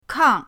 kang4.mp3